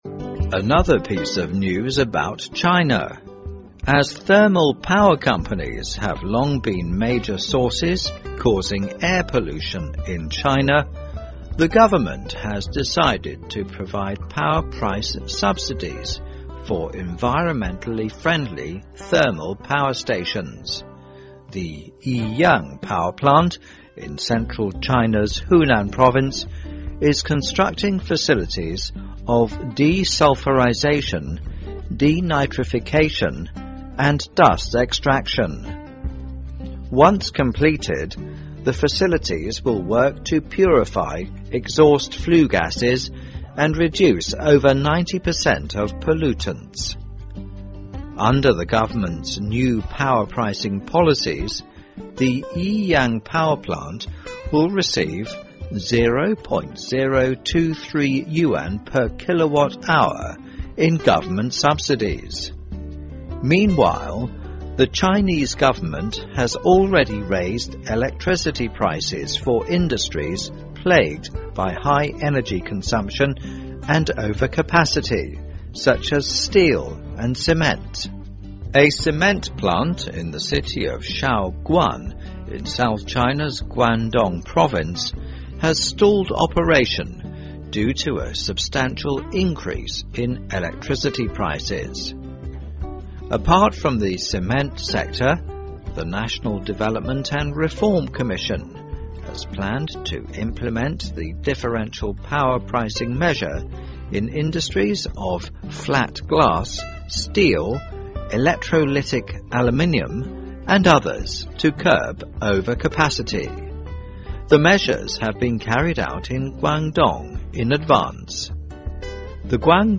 News Plus慢速英语:发改委出台差别电价政策 化解产能过剩促减排